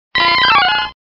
Archivo:Grito de Mankey.ogg
actual17:07 26 oct 20140,9s (14 kB)PoryBot (discusión | contribs.)Actualizando grito en la sexta generación (XY)